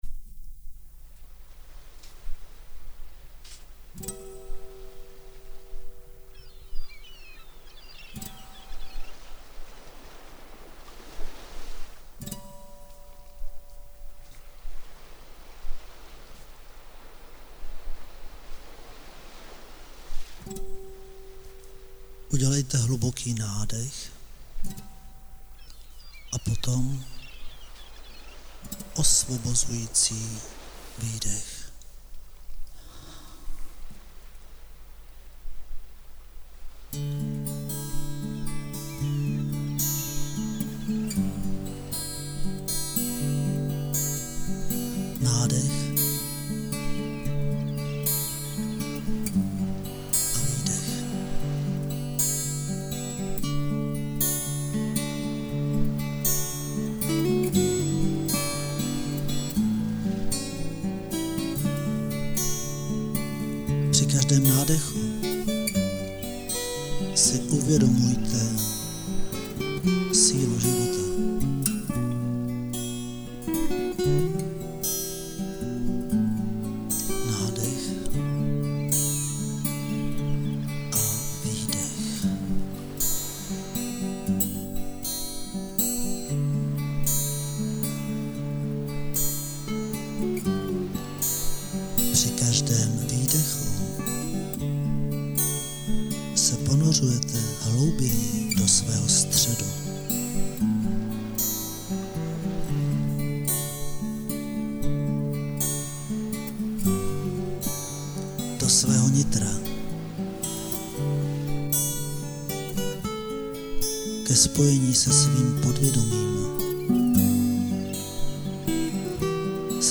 Block-time s kytarou